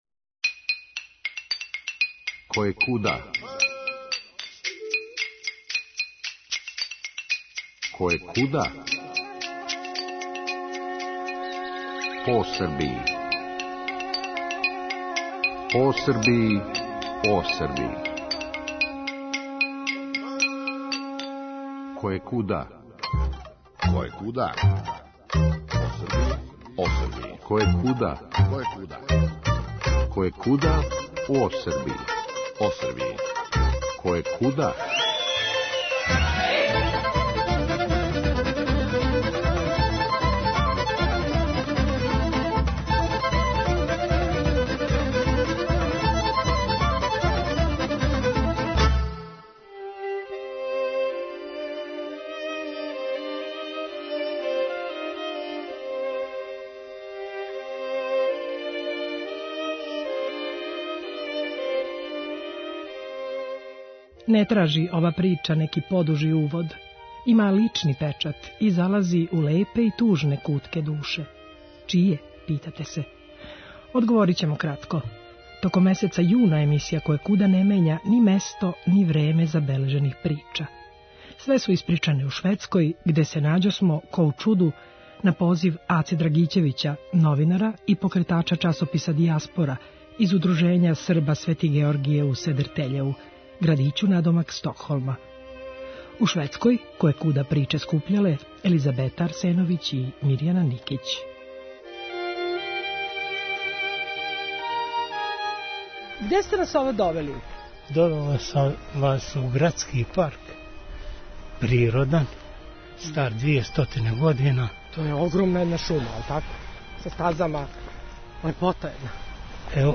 Све су испричане у Шведској